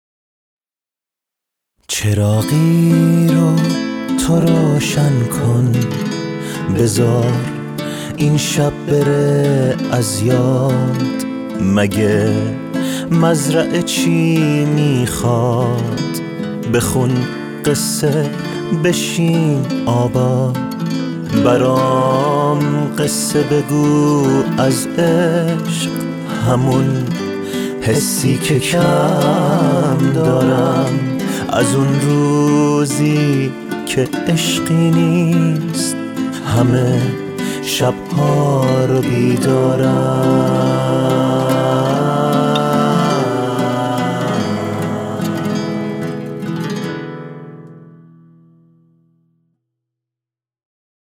تیتراژ پادکست